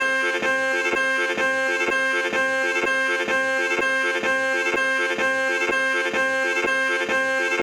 My method for initial practice was I created a looped part of the middle that goes s 6-beat-6-beat-6-beat, whether with bending or with these, these “small looped parts” are my secret weapon: